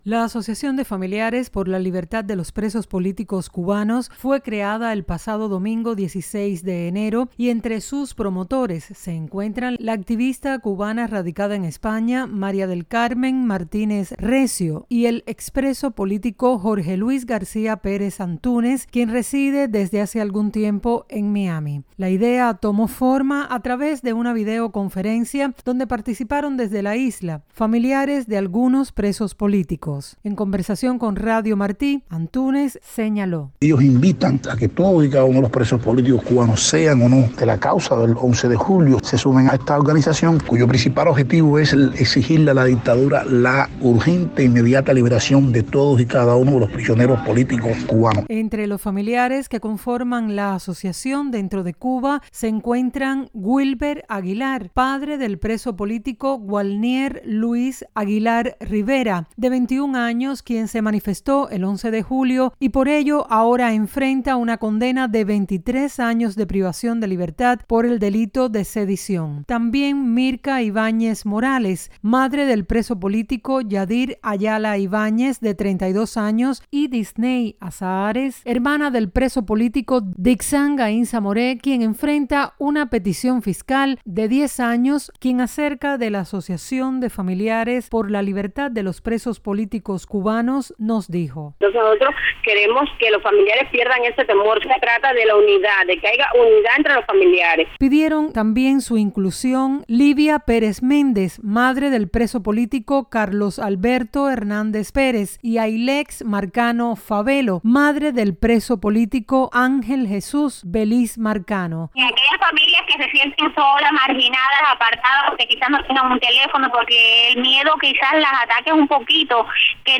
pudimos conversar acerca de la recién fundada asociación de familiares por la libertad de los presos políticos cubanos.